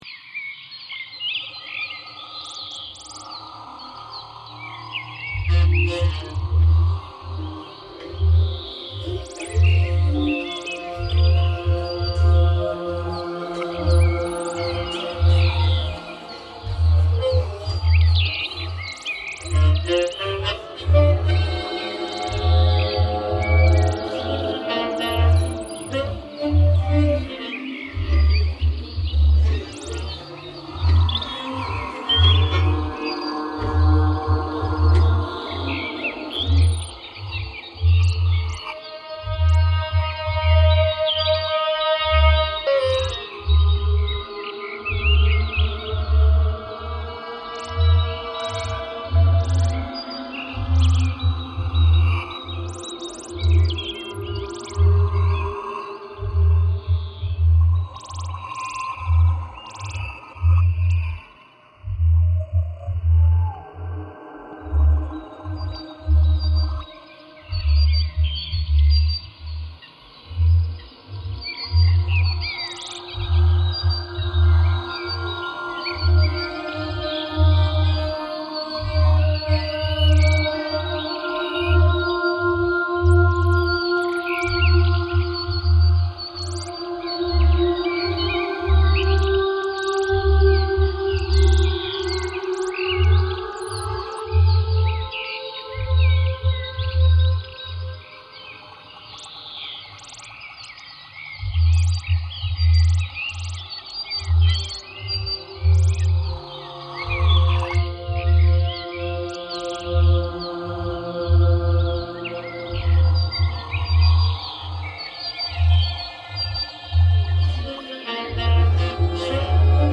Drinkery in New Orleans reimagined